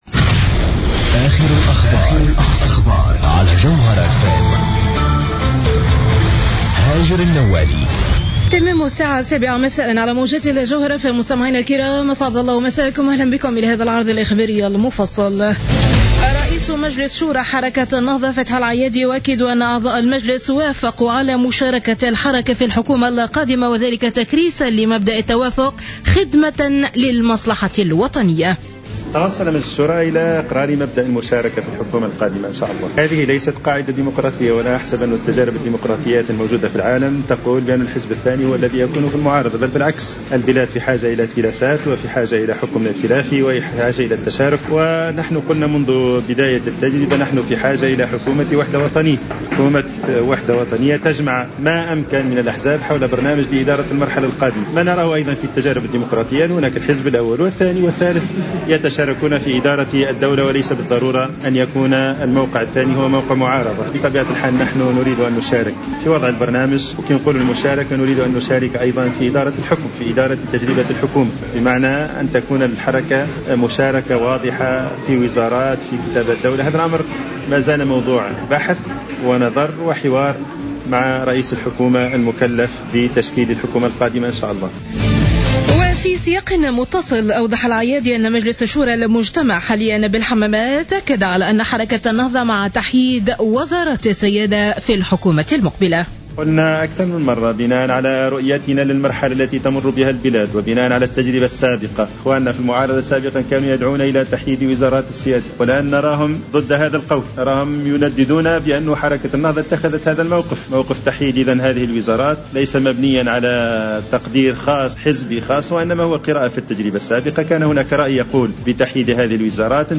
نشرة أخبار السابعة مساء ليوم السبت 10-01-15